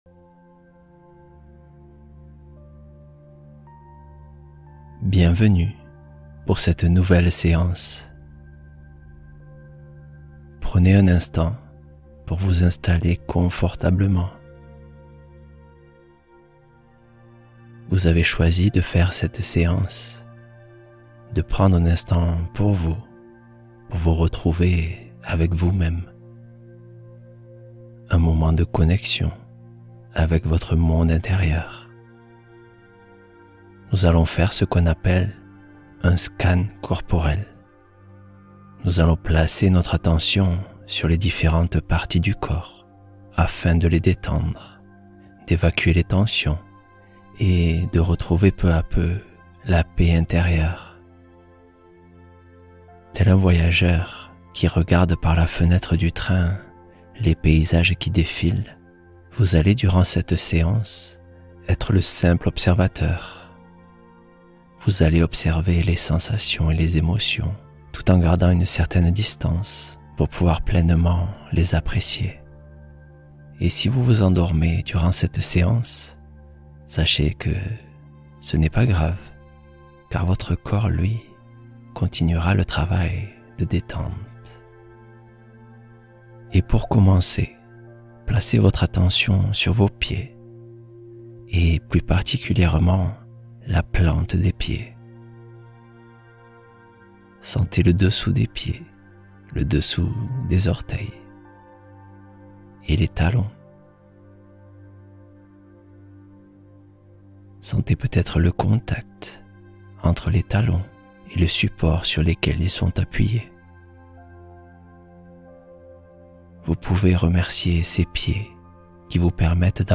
Repos profond — Méditation pour apaiser l’ensemble du corps